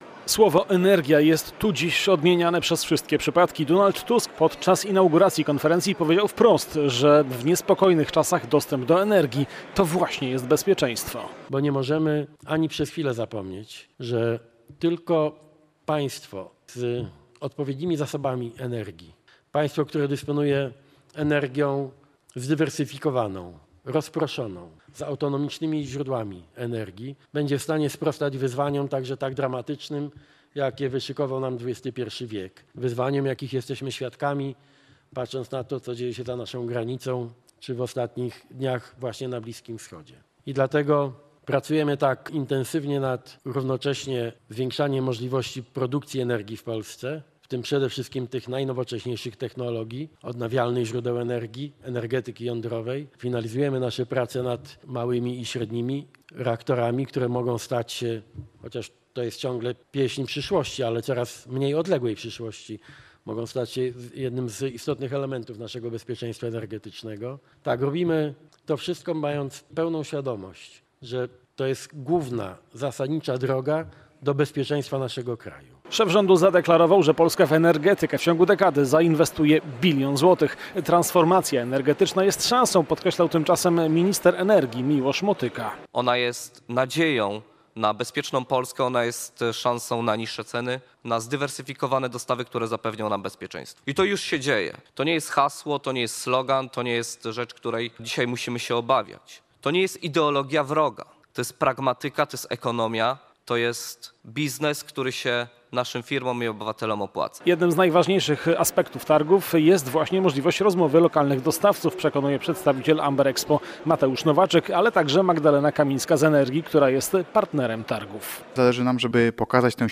W środę premier Donald Tusk bierze udział w odbywającej się w Gdańsku konferencji „Power Connect”.